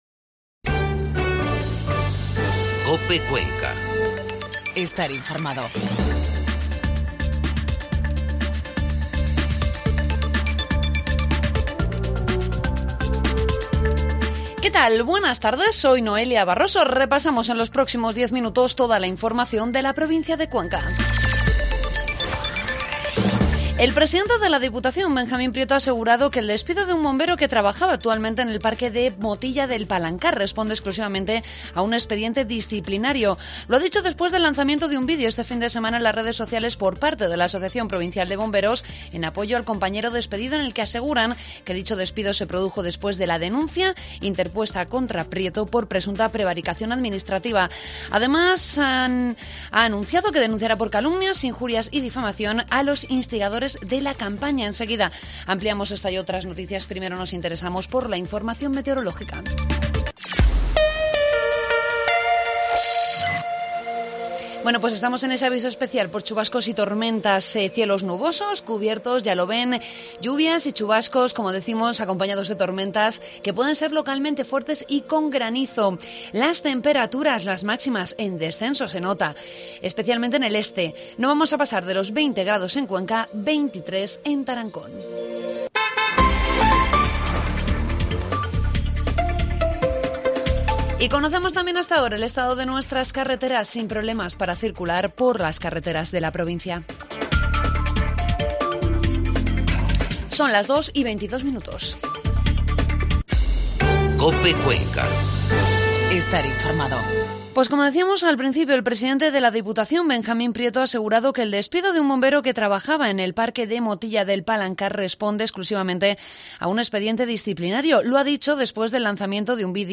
AUDIO: Informativo COPE Cuenca